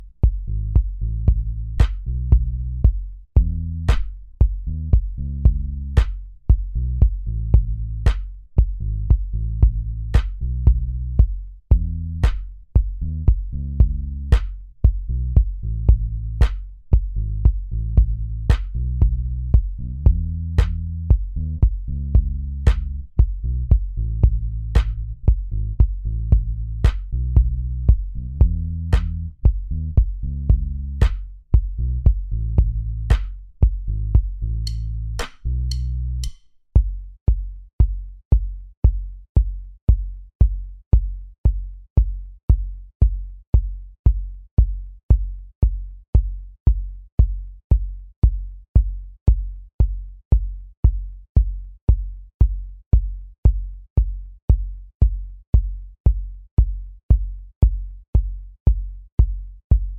Minus Main Guitar For Guitarists 3:57 Buy £1.50